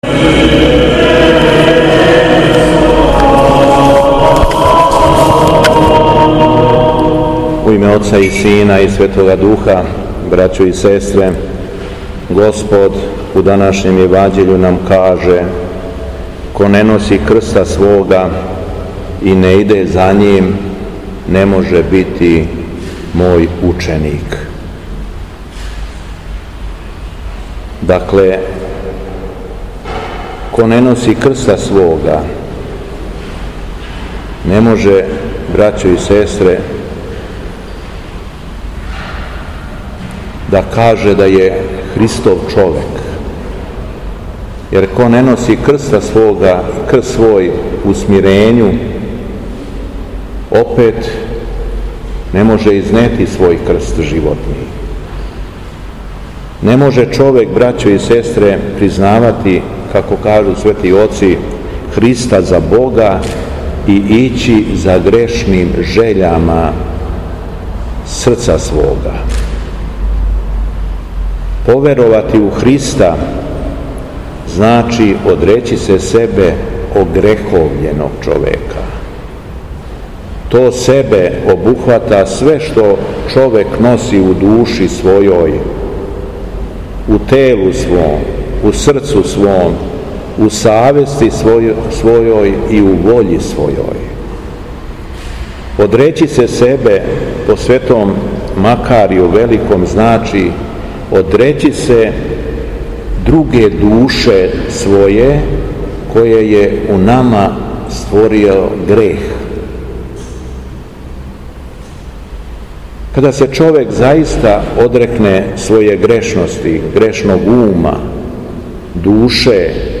Беседа Његовог Преосвештенства Епископа шумадијског г. Јована
После прочитаног јеванђелског зачала од јеванђелисте Луке, преосвећени владика се обратио беседом сабраном народу: